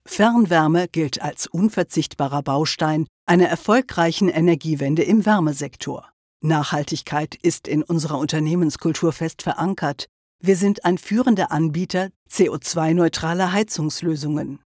Mit ihrer tiefen, samtigen Charakterstimme gibt die Sprecherin jedem Script einen individuellen Touch.
Sprechprobe: Industrie (Muttersprache):
With her deep, velvety character voice, the speaker gives every script an individual touch.
Writers and listeners alike appreciate the varied realisation of her texts and the outstanding audio quality of the recordings produced in her own studio.